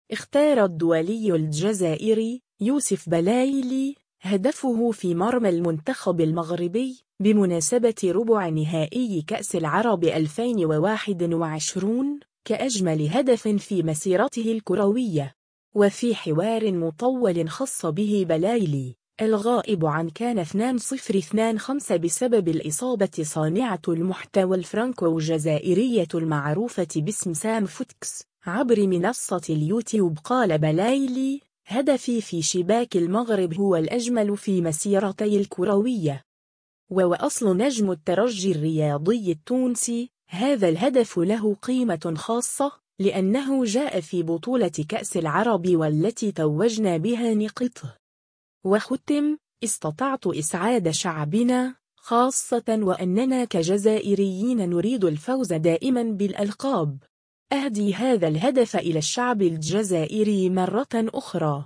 حوار مطول